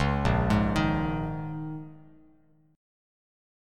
A#m11 chord